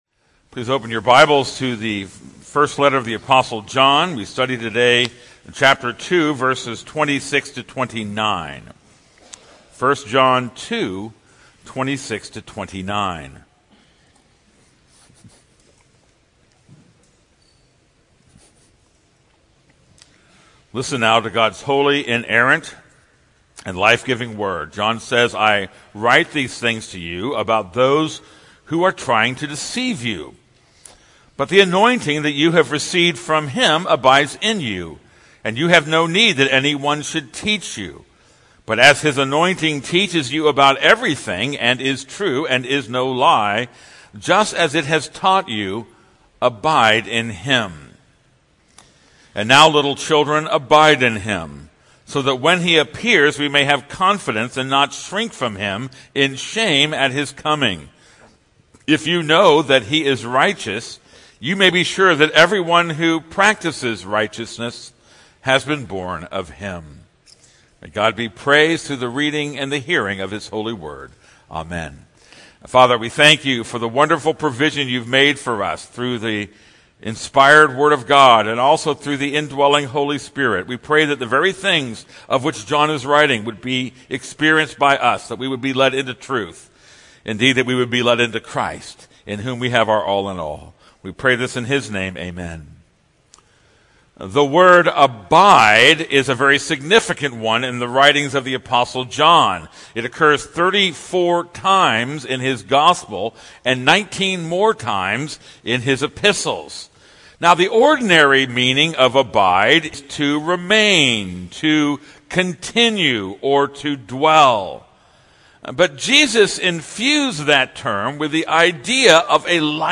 This is a sermon on 1 John 2:26-29.